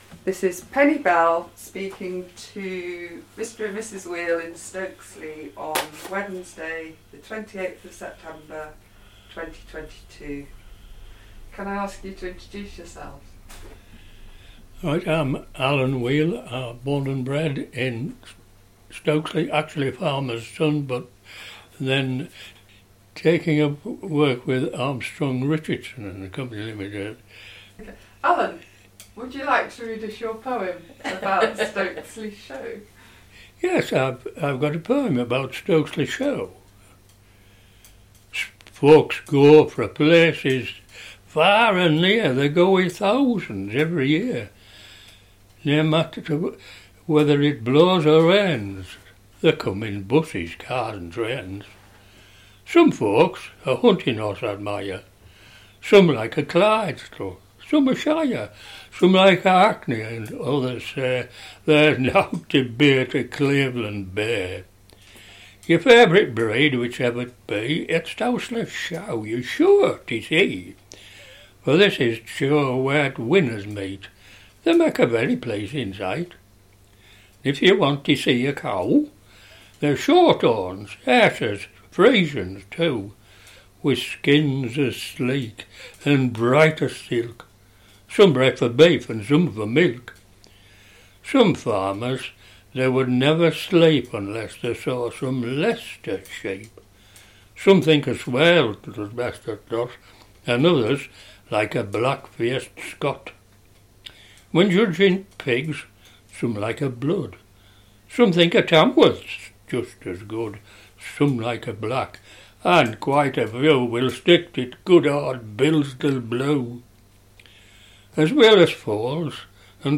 Digital recording of oral history interview